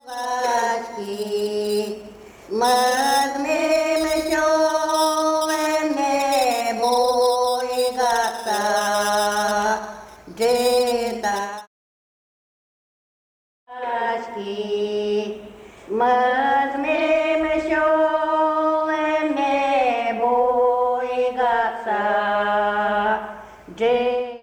Вследствие чего при воспроизведении слышен скрежет, или скрип. Размещенный ниже образец1 наглядно отражает наличие и отсутствие лязгающего звука:
tapedry-skrezhet.ogg